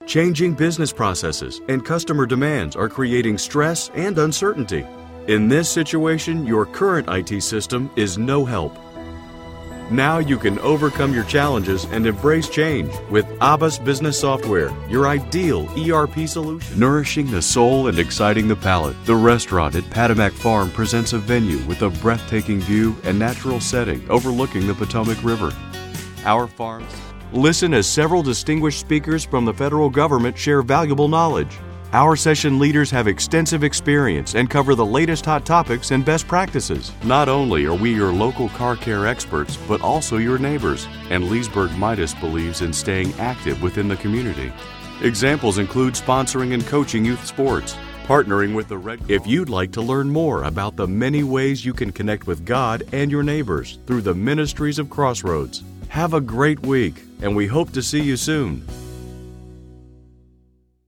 mid-atlantic
Sprechprobe: Industrie (Muttersprache):